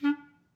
Clarinet
DCClar_stac_D3_v2_rr1_sum.wav